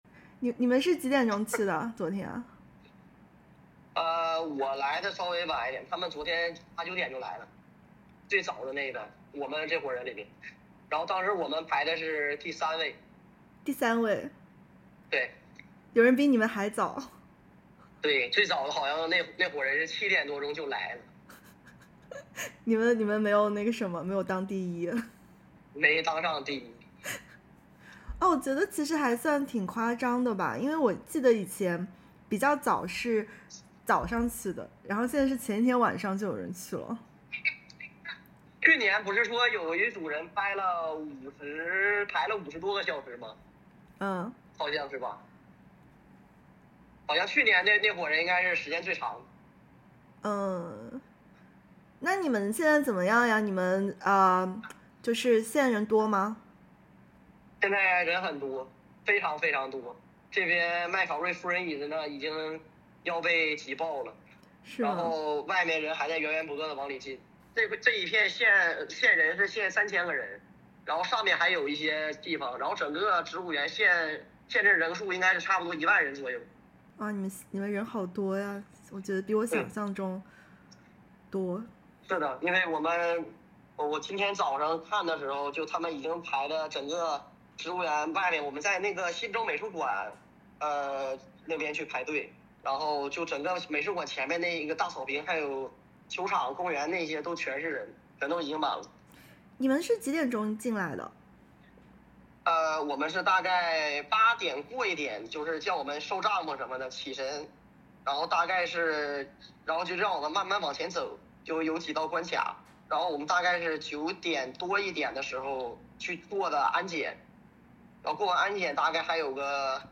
在麦考瑞夫人椅子的排队从30号晚上就开始了 (点击音频收听详细报道)。